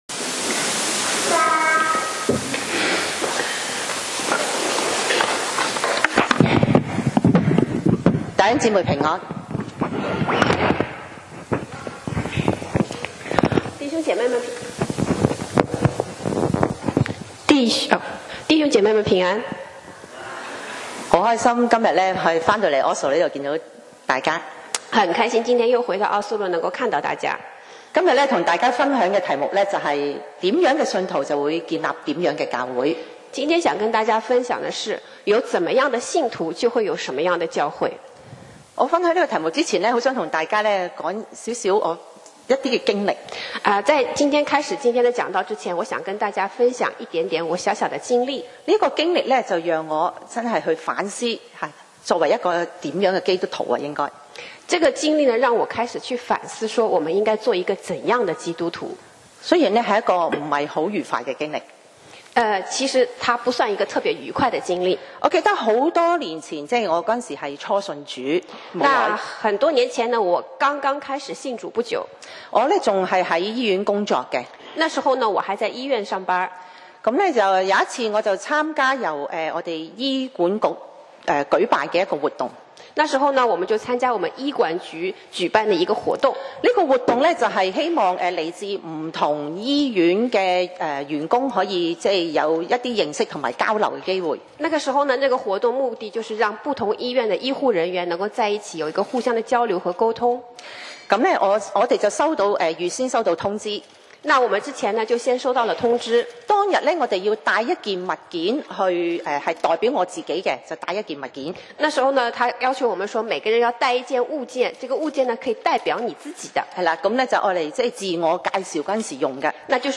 講道 Sermon 題目 Topic：怎樣的信徒 建立怎樣的教會 經文 Verses：帖撒羅尼迦前書1: 1-3. 1保羅、西拉、提摩太、寫信給帖撒羅尼迦在父 神和主耶穌基督裡的教會．願恩惠平安歸與你們。